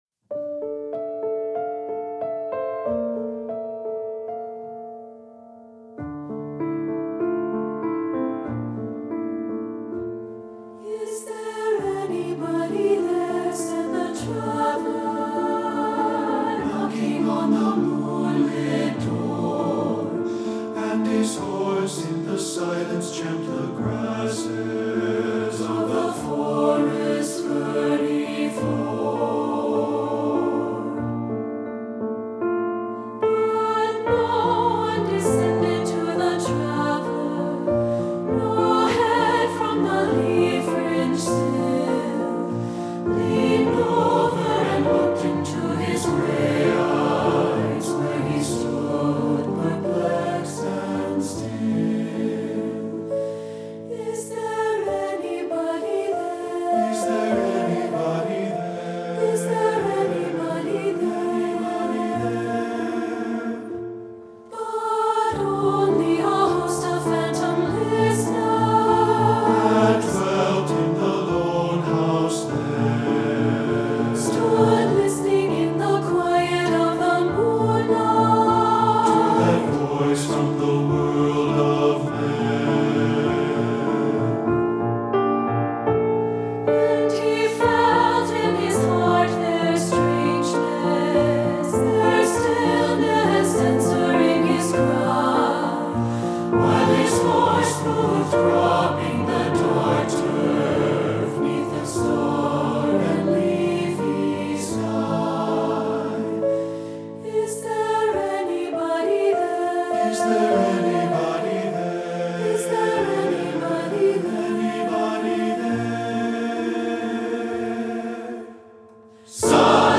Choral Concert/General
SATB Audio